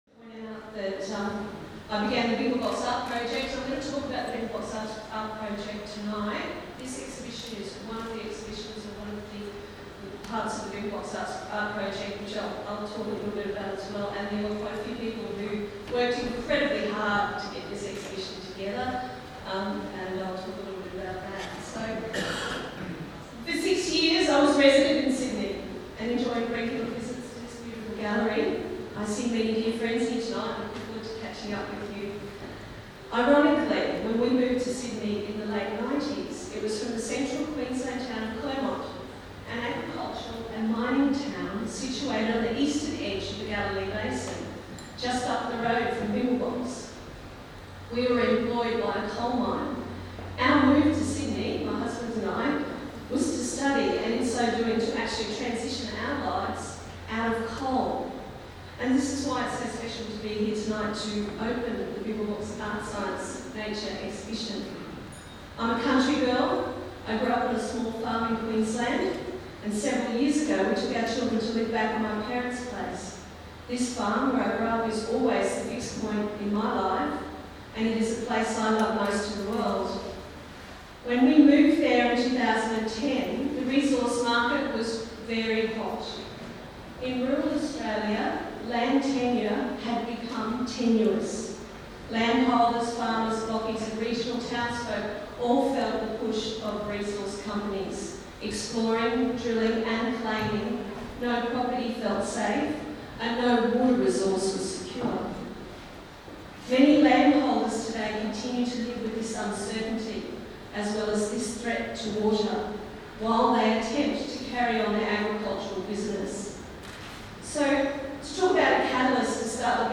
opening speech